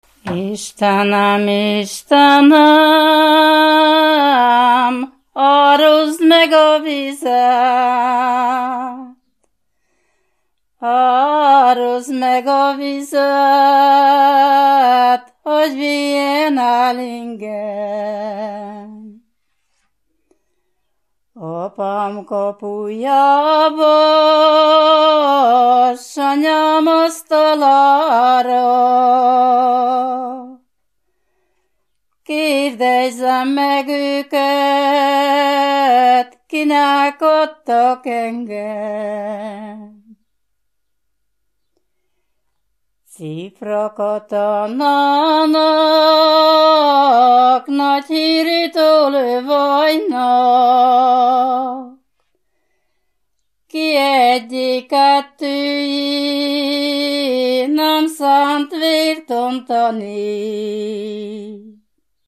Moldva és Bukovina - Moldva - Pusztina
Műfaj: Ballada
Stílus: 3. Pszalmodizáló stílusú dallamok
Szótagszám: 6.6.6.6
Kadencia: 5 (b3) X 1